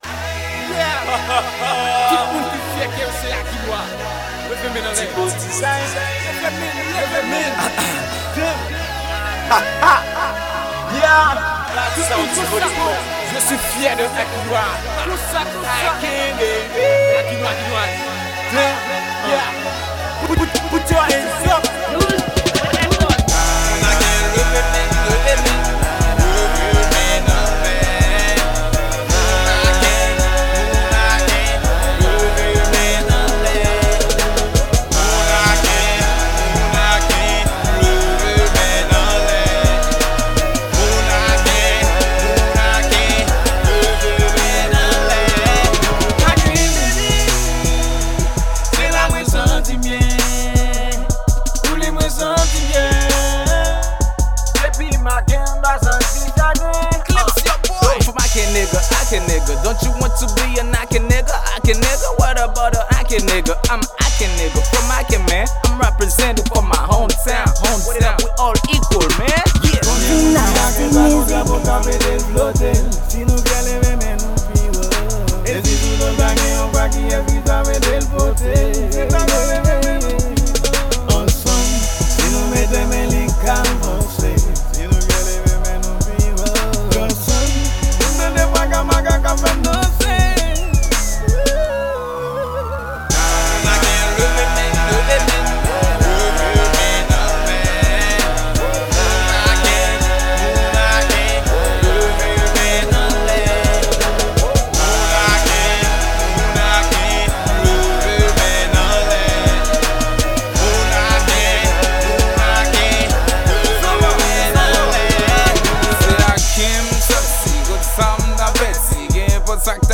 Genre: Hip Hop & Dancall.